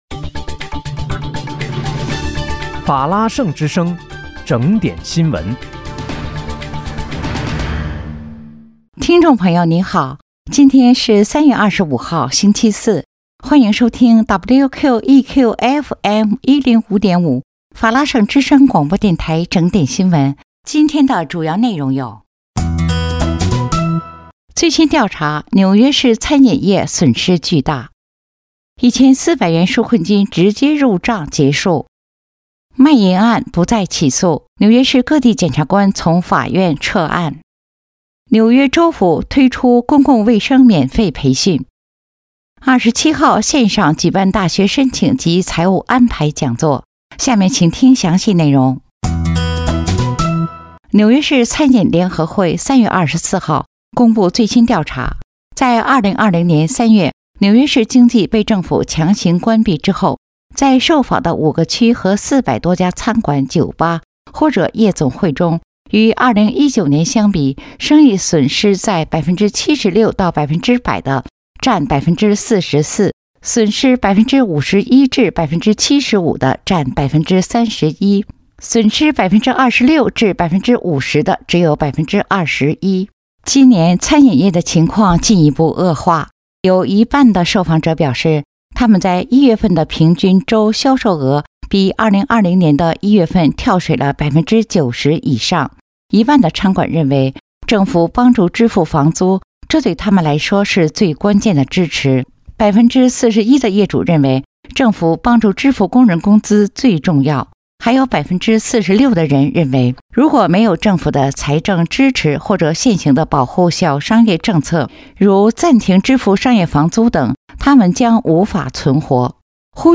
3月25日（星期四）纽约整点新闻